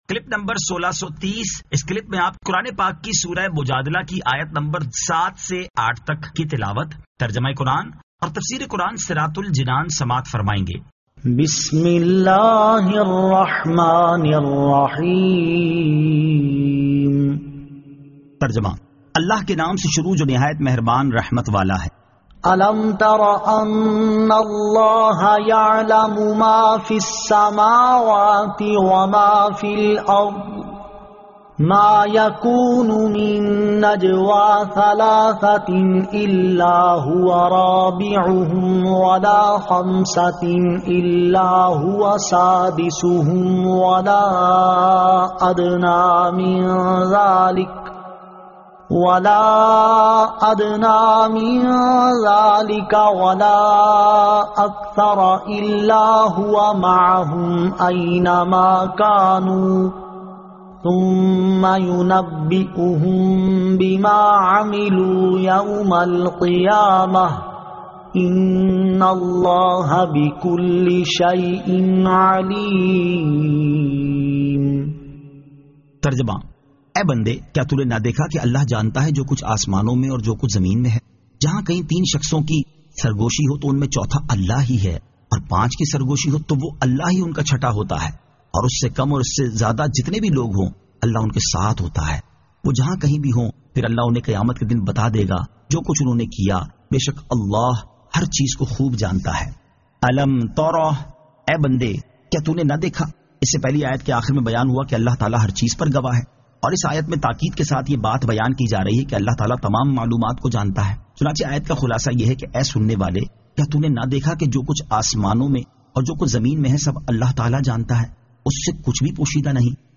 Surah Al-Mujadila 07 To 08 Tilawat , Tarjama , Tafseer